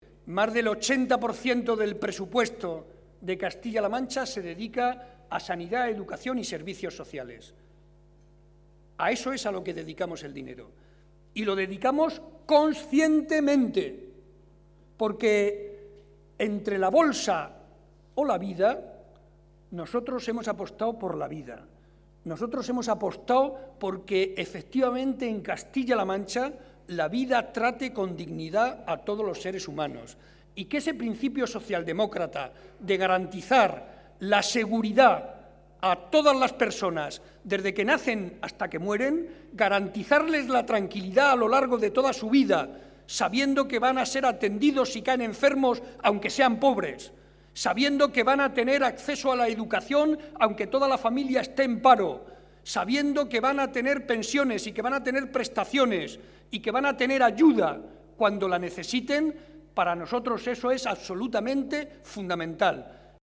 Estas son algunas de las manifestaciones que ha hecho esta noche el secretario regional del PSOE y presidente de Castilla-La Mancha, José María Barreda, durante el transcurso de la tradicional cena de Navidad del PSOE de Guadalajara y que ha congregado a más de 400 afiliados y simpatizantes.